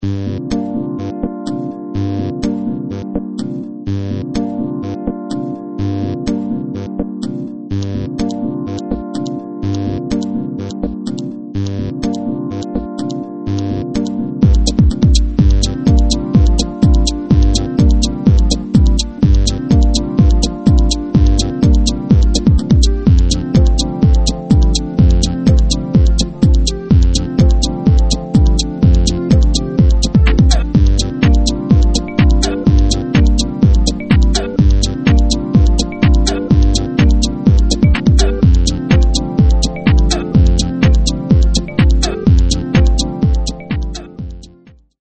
2005 smooth medium instr.